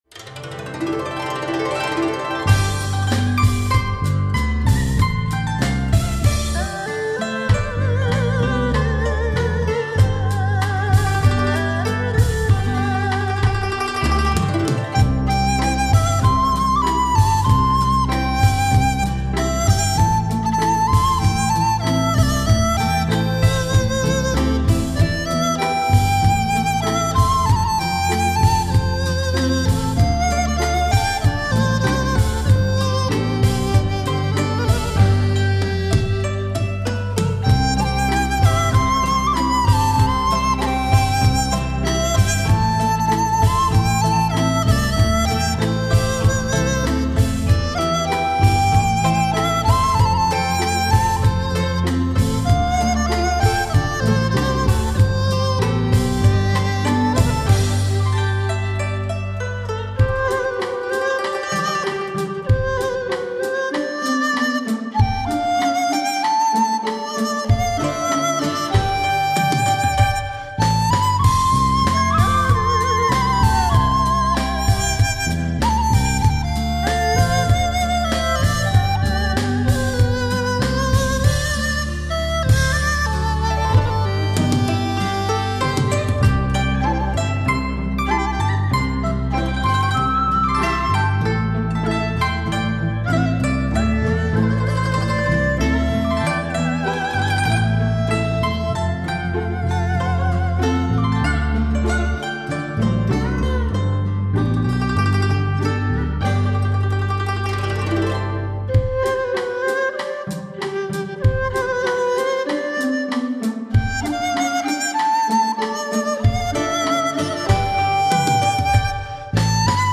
等歌曲旋律欢快、音色高亢明亮，非常适合笛子
增加了二胡、古筝、琵琶、大提琴，小提琴等各种不同的乐器与主奏乐器进行对话、互动，